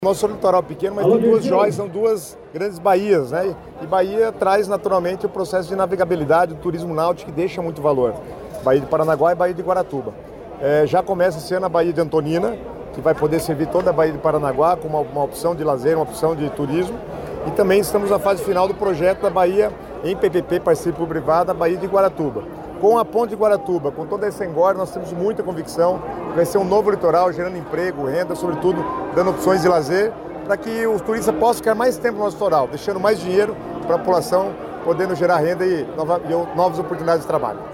Sonora do secretário Estadual das Cidades, Guto Silva, sobre o projeto da nova marina em Pontal do Paraná | Governo do Estado do Paraná